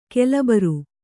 ♪ kelabaru